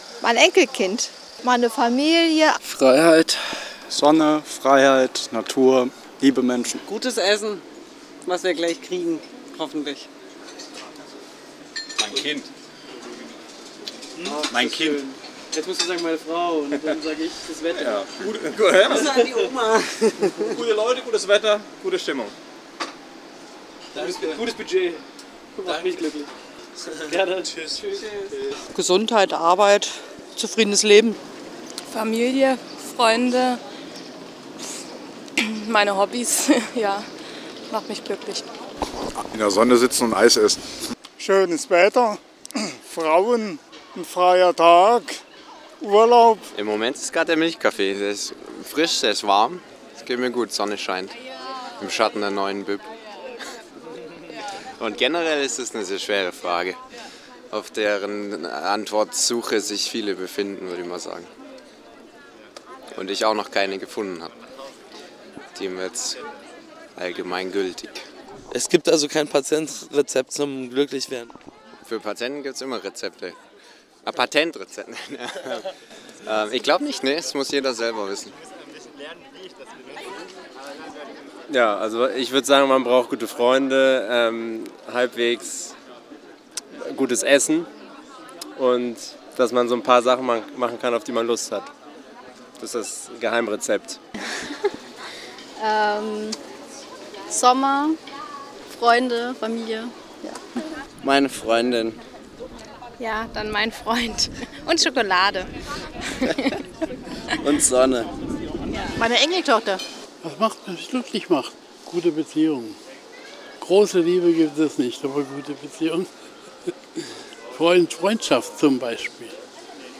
Glück - eine kleine Umfrage